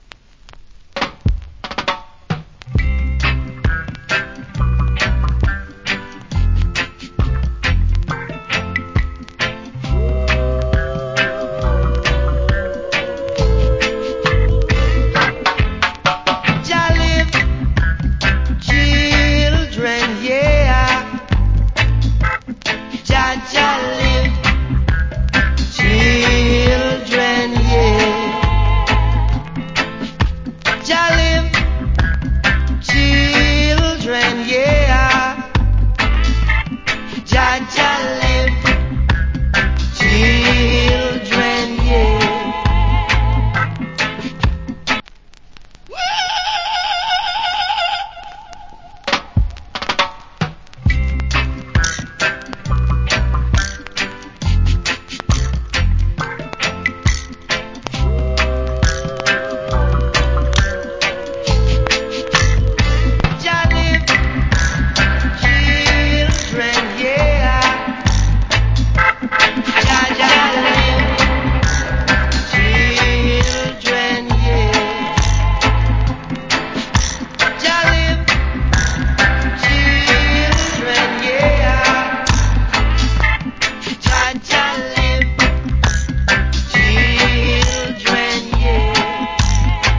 Roots Rock Vocal.